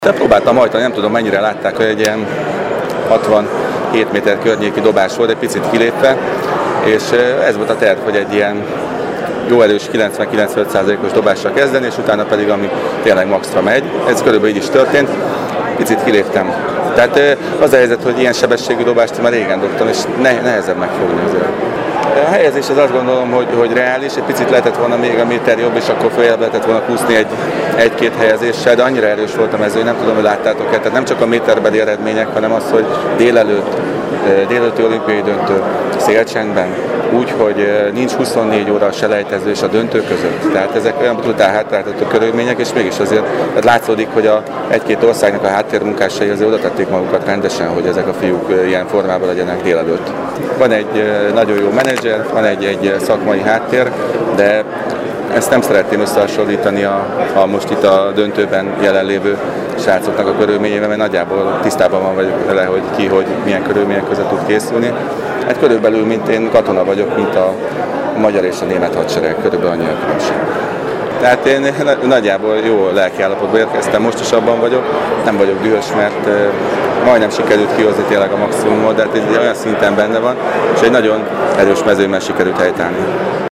A folytatásban sajnos öt kísérletből, négyszer is kilépett, így végül a hetedik helyen végzett. Kővágó Zoltán a verseny után a Marosvásárhelyi Rádiónak, így nyilatkozott: